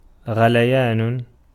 ملف تاريخ الملف وصلات معلومات الصورة (ميتا) Ar-غليان.ogg  (Ogg Vorbis ملف صوت، الطول 1٫6ث، 113كيلوبيت لكل ثانية) وصف قصير ⧼wm-license-information-description⧽ Ar-غليان.ogg English: Pronunciation of word "غليان" in Arabic language. Male voice. Speaker from Tiznit, Morocco.
Ar-غليان.ogg.mp3